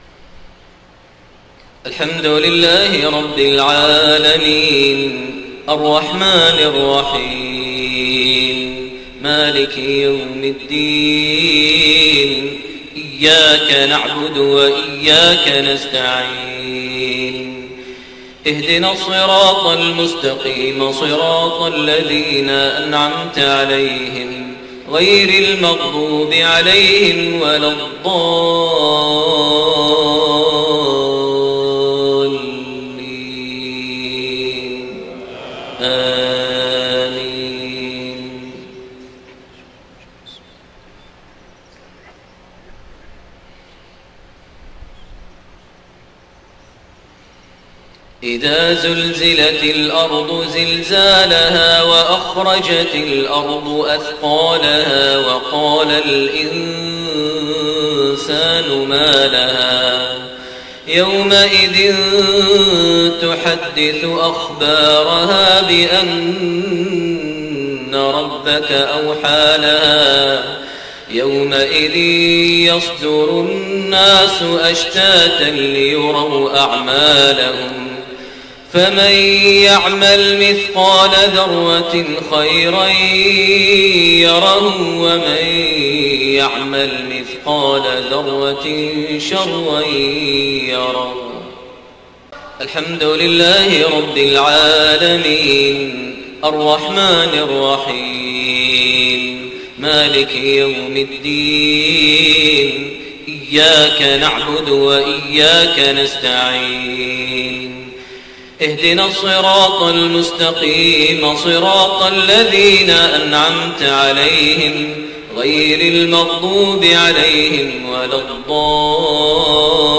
Maghrib prayer from Surat Az-Zalzala and Al-Qaari'a > 1430 H > Prayers - Maher Almuaiqly Recitations